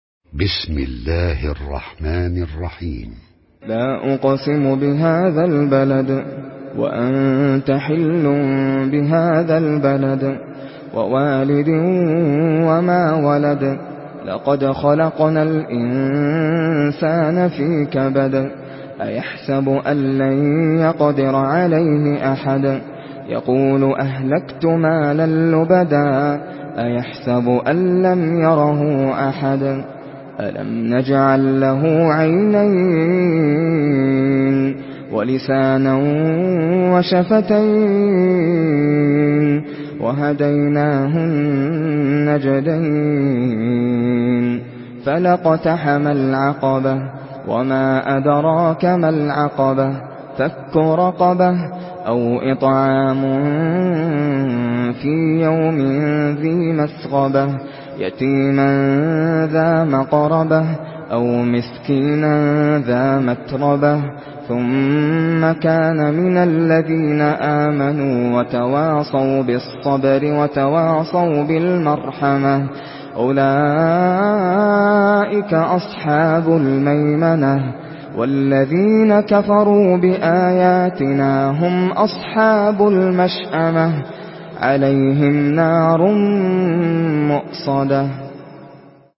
Surah Al-Balad MP3 in the Voice of Nasser Al Qatami in Hafs Narration
Murattal Hafs An Asim